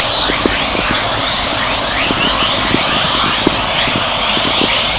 Examples of ELF/VLF Waves observed in Antarctica
Dawn chorus (108kB .wav file)5 s: Halley, 1020:20-:25 UT 21 July 1998